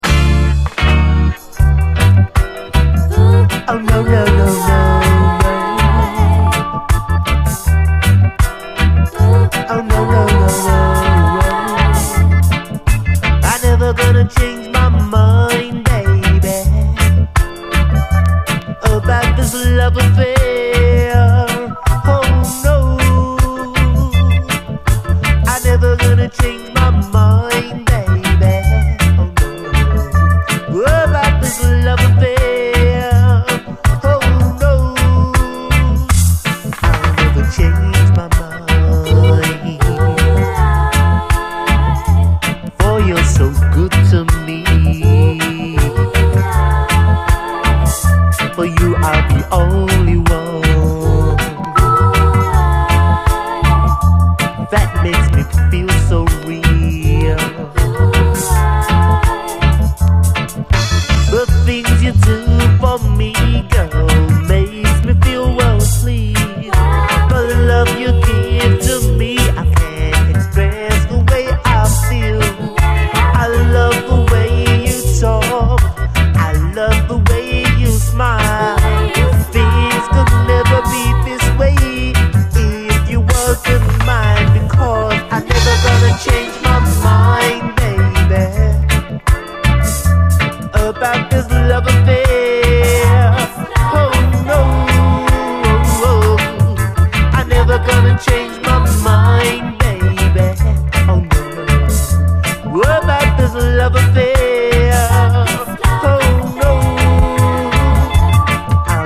REGGAE
男性シンガーによるネットリとソウルフルなUKラヴァーズ！女性コーラスの絡み方はドリーミー！